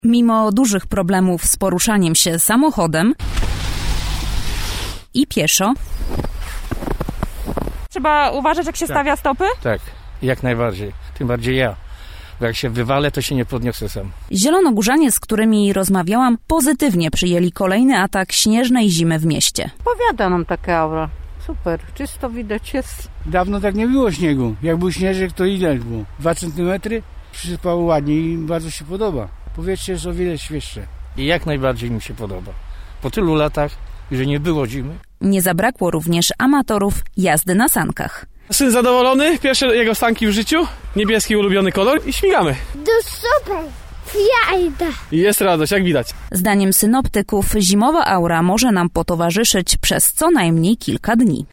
Zielonogórzanie o kolejnym ataku śnieżnej zimy